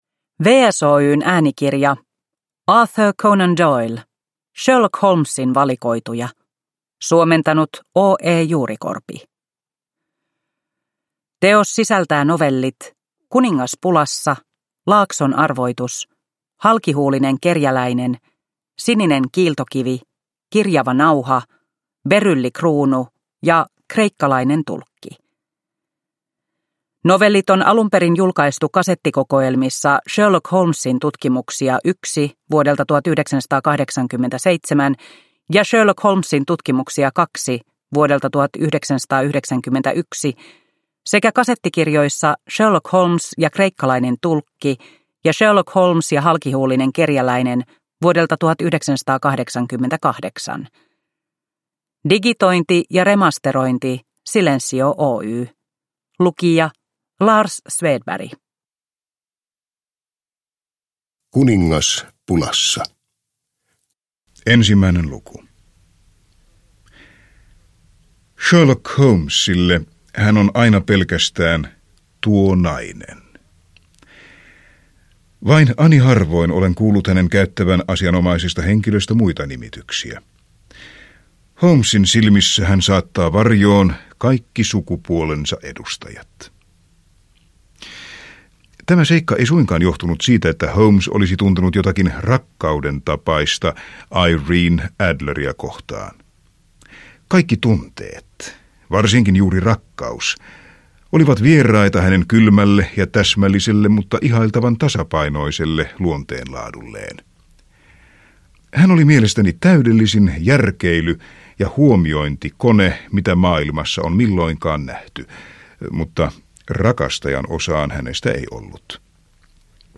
Sherlock Holmesin valikoituja – Ljudbok